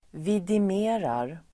Ladda ner uttalet
Uttal: [vidim'e:rar]